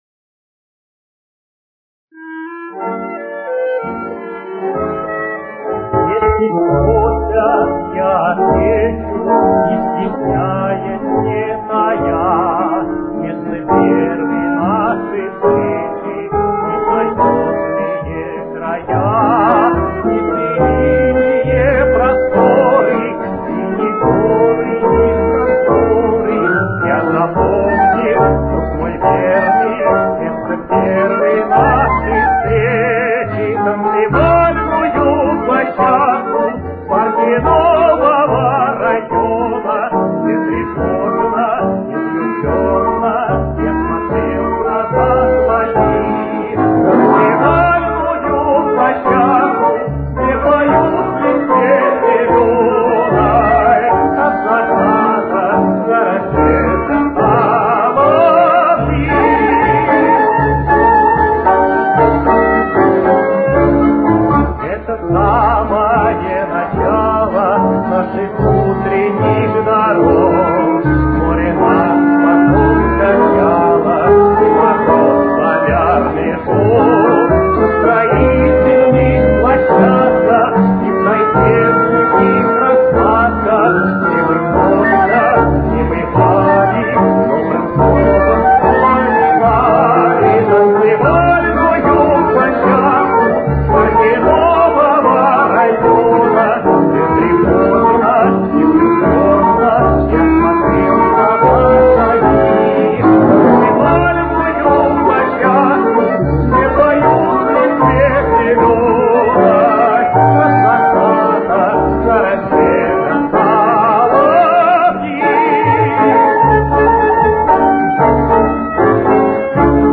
тенор
с очень низким качеством (16 – 32 кБит/с)
Темп: 215.